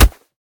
kick1.ogg